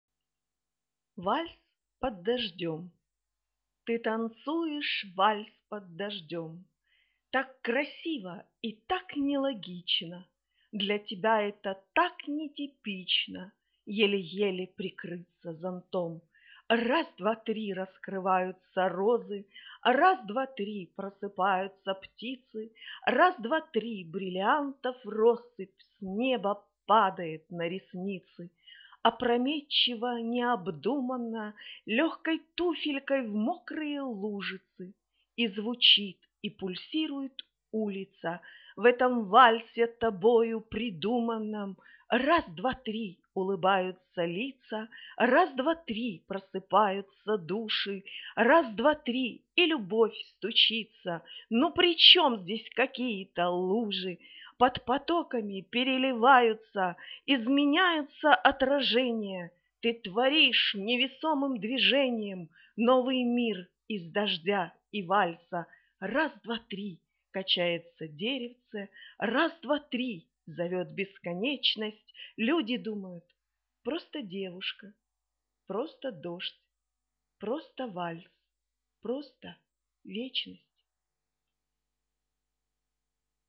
Вальс под дождем (вслух)
39 голос просто чудовий і так красиво написано